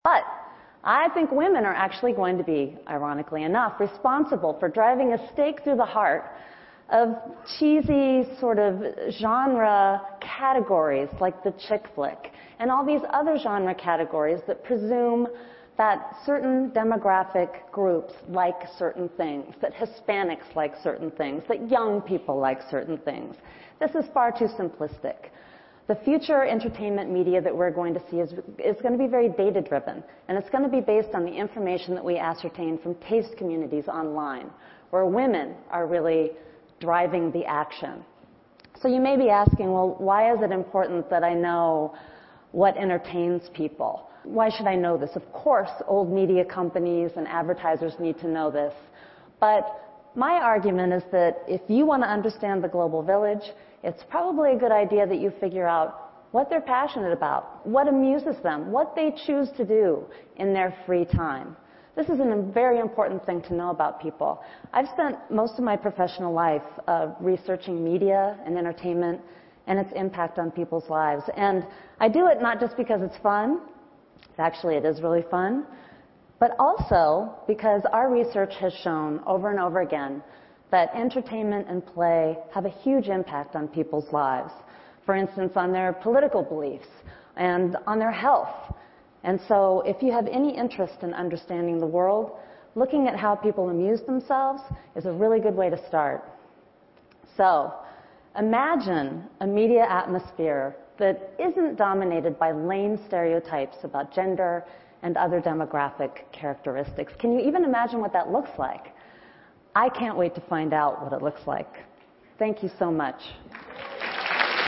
TED演讲:社交传媒和性别消失(3) 听力文件下载—在线英语听力室